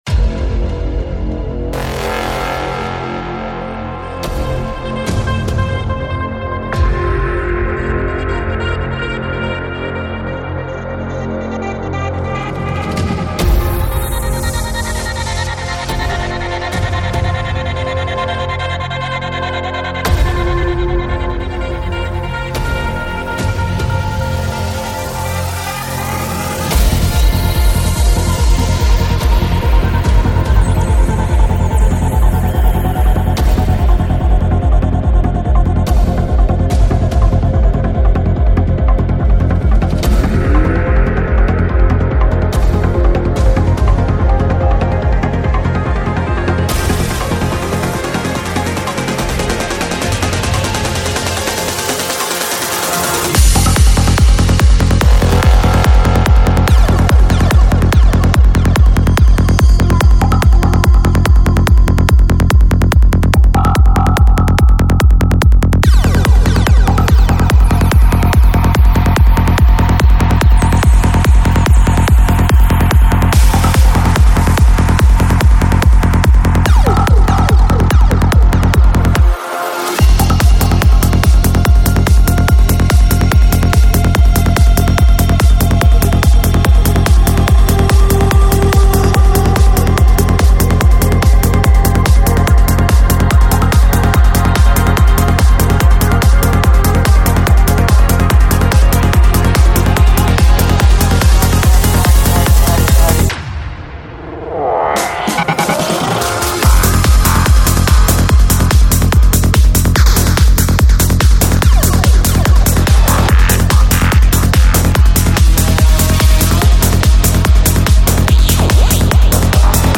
Жанр: Psychedelic
Psy-Trance